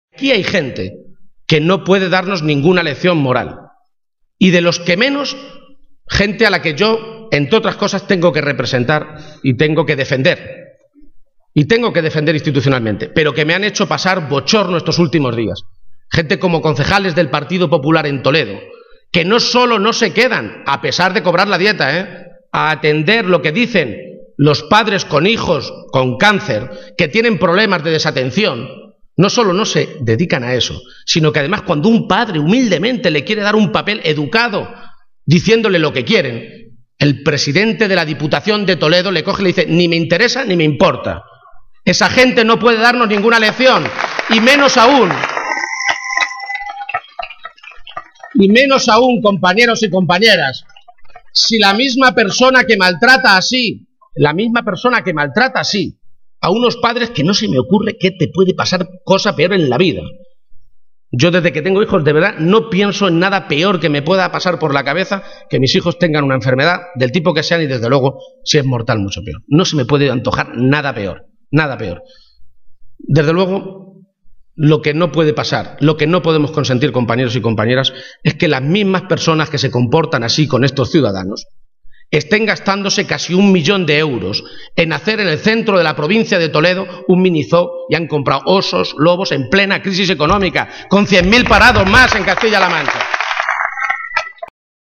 En ese acto público, con el Teatro Victoria abarrotado, Page ha dado toda una serie de argumentos por los que asegurar que una victoria del PSOE sobre el PP el domingo es importante.